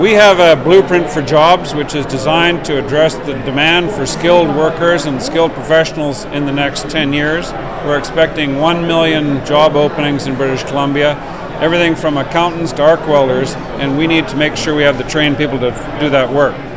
Goat News spoke with Minister of Advanced Education Andrew Wilkinson. He explains the importance of the blueprint.